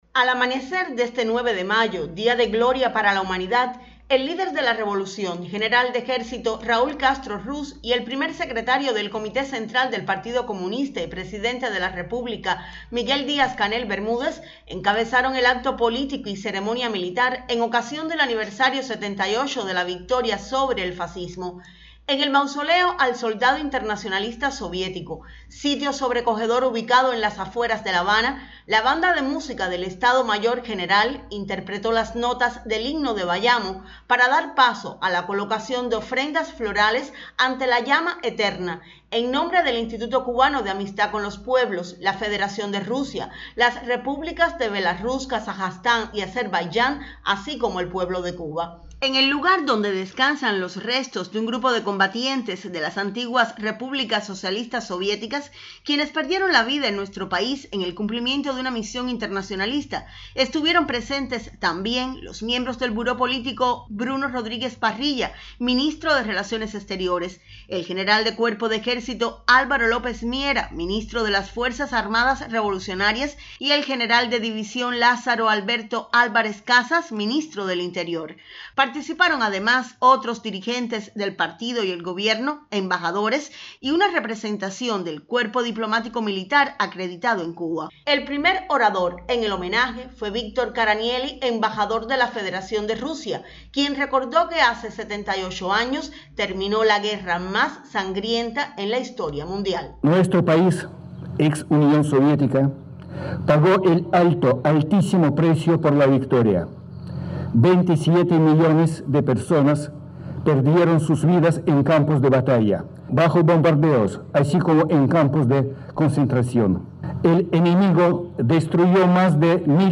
Raúl y Díaz-Canel encabezaron conmemoración de la victoria sobre el fascismo
acto_victoria_sobre_fascismo.mp3